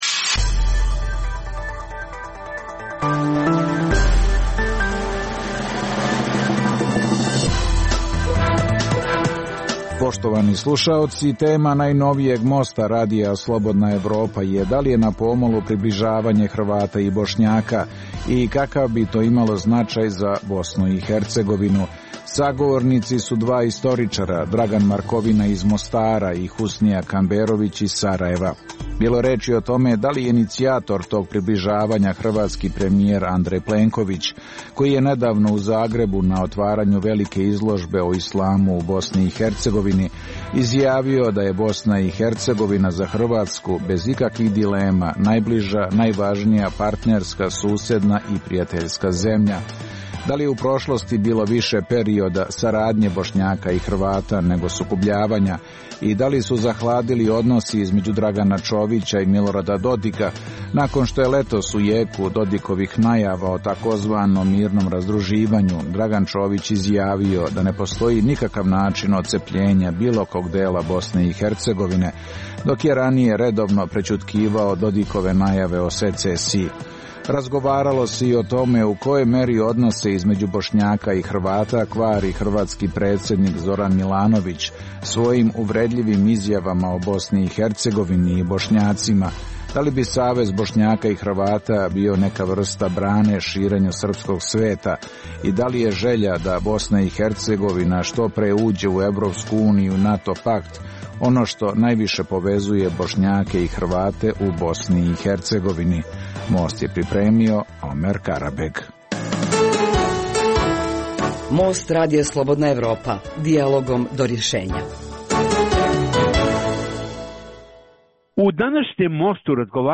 Tema najnovijeg Most Radija Slobodna Evropa bila da li je na pomolu približavanje Hrvata i Bošnjaka i kakav bi to imalo značaj za Bosnu i Hercegovinu. Sagovornici su bili dva istoričara